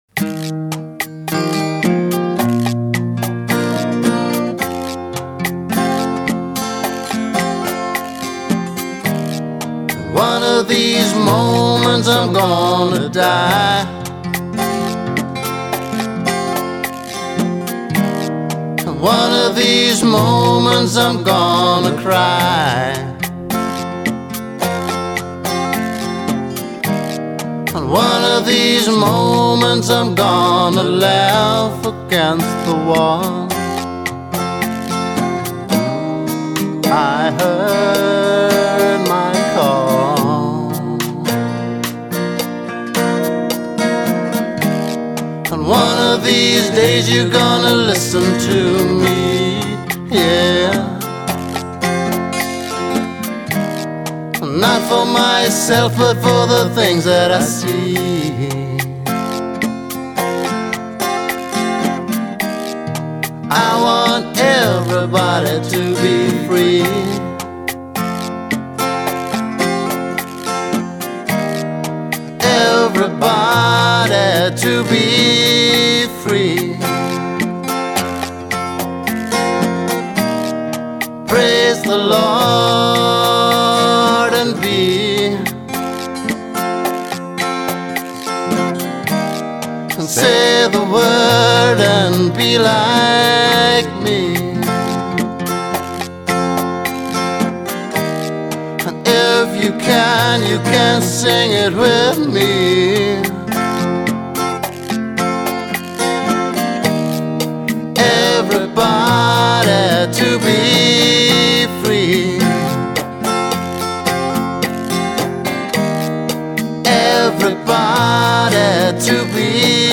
Rockhymne, d + 2g + 3voc, Song # 29, mp3